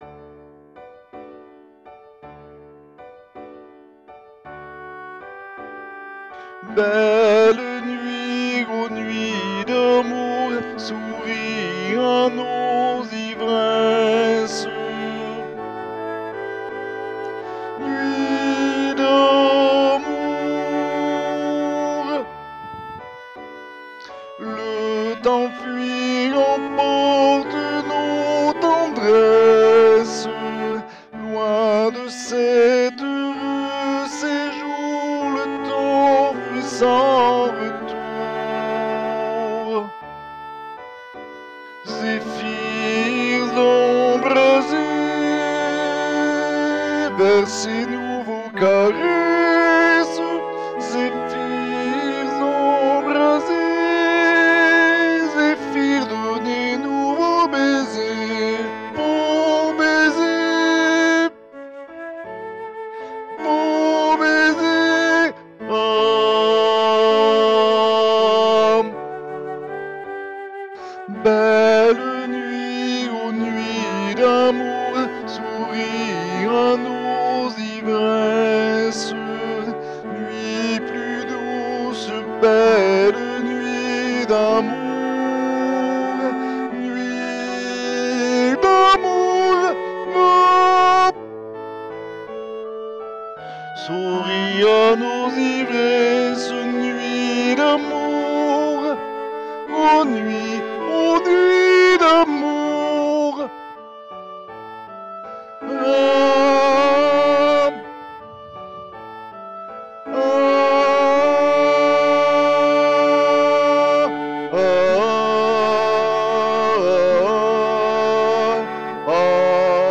voix chantée
Tenors-Barcarolle-des-contes-dHoffmann.mp3